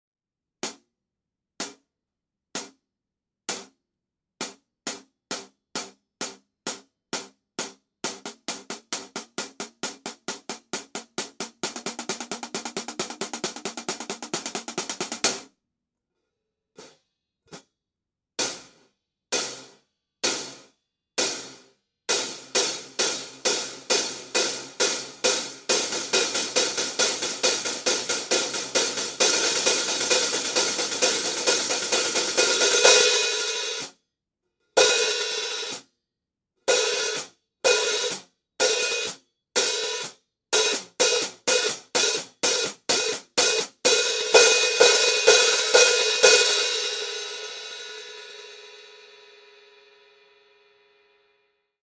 New for 2018, the Armor Series Hi-hats provide great controlled sound with a nice resonance.
14″ Armor Hi-Hat Cymbal: Approx 2365 Grams Combined.